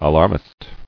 [a·larm·ist]